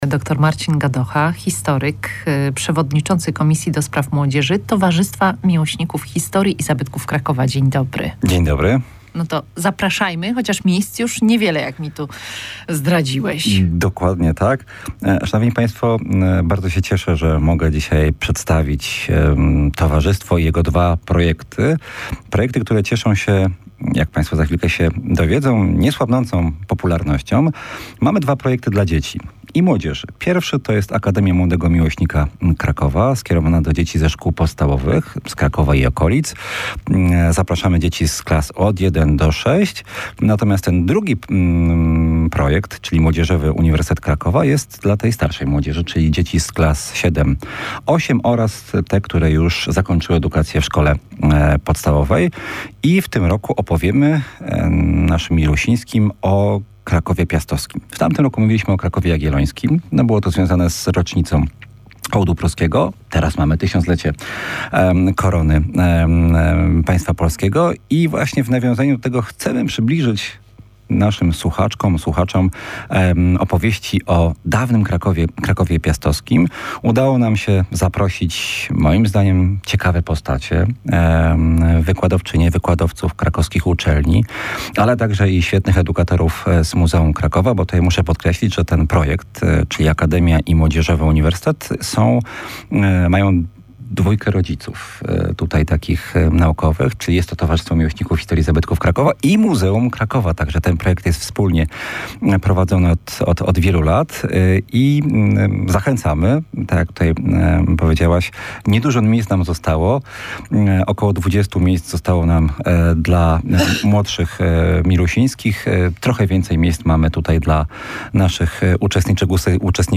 rozmawiała w audycji Przed Hejnałem